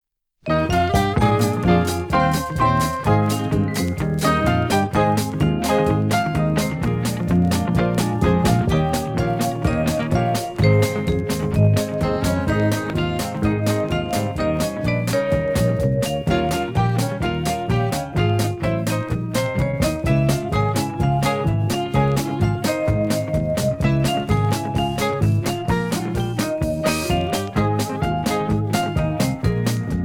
Singing Call
Inst